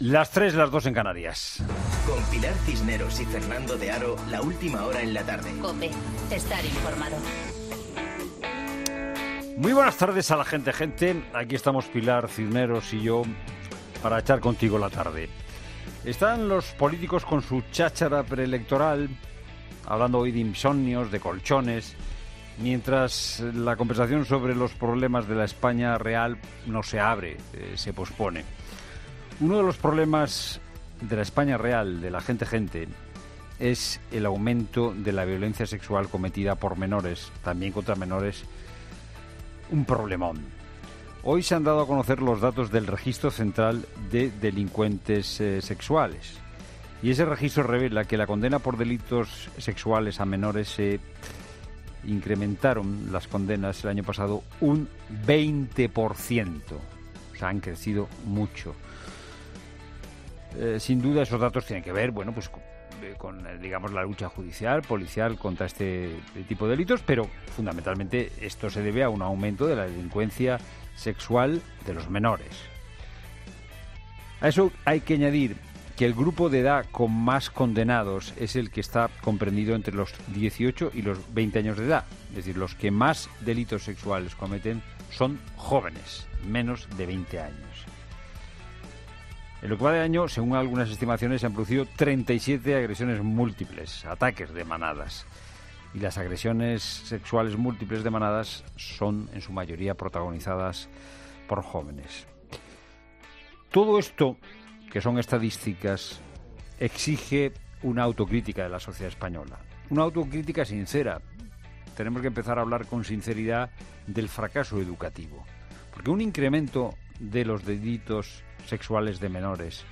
Monólogo de Fernando de Haro
El presentador de La Tarde analiza la actualidad en su monólogo